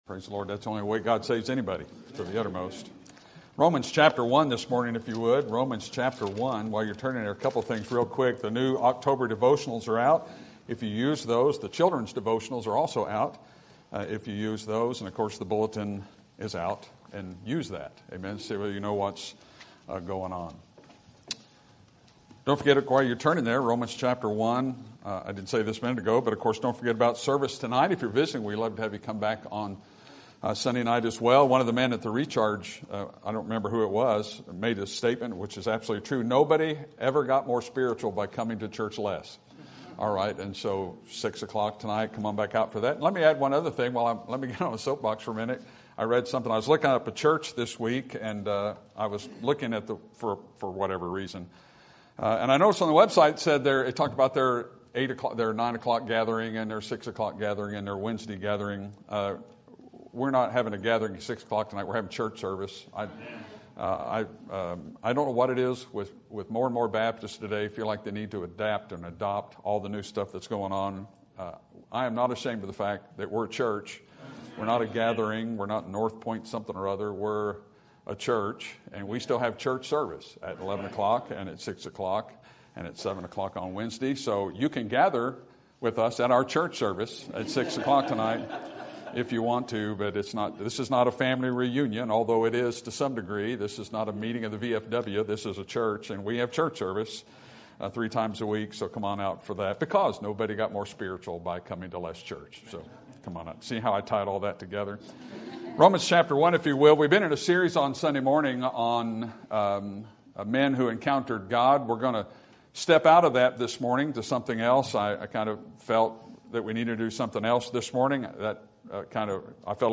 Sermon Date
Sermon Topic: General Sermon Type: Service Sermon Audio: Sermon download: Download (24.42 MB) Sermon Tags: Romans Witnesses Salvation Gospel